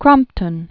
(krŏmptən), Samuel 1753-1827.